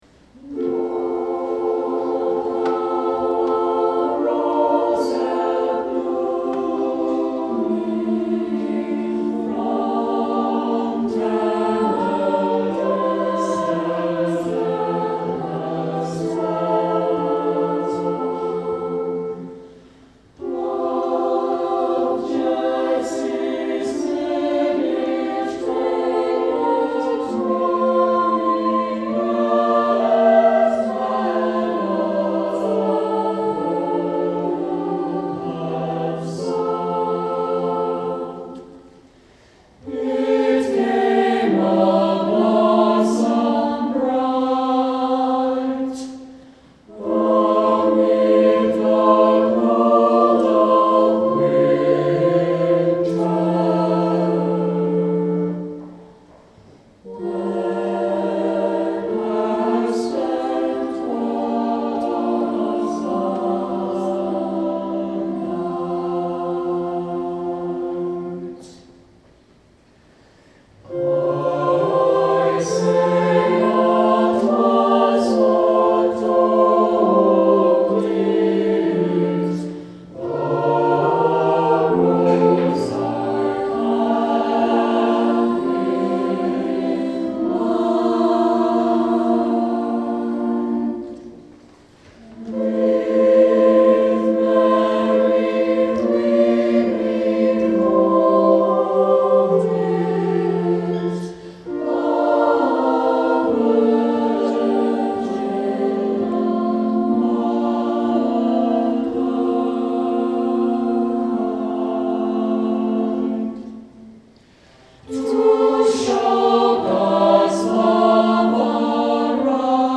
Saint Clement Choir Sang this Song